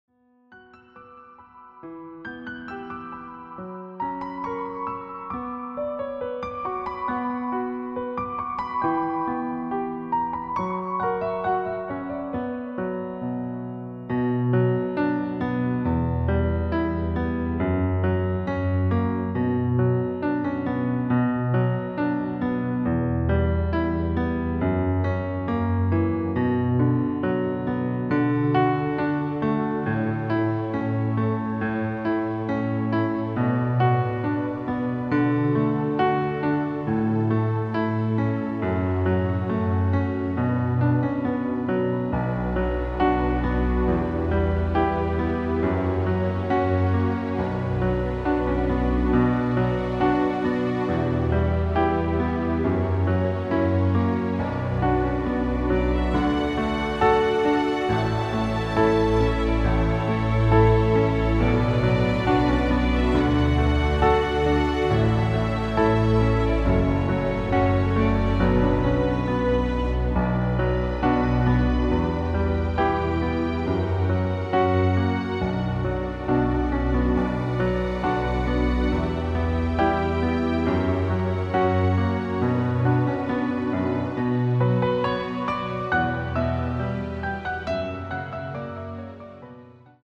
Klavierversion
• Art: Klavier Streicher
• Das Instrumental beinhaltet NICHT die Leadstimme
Klavier / Streicher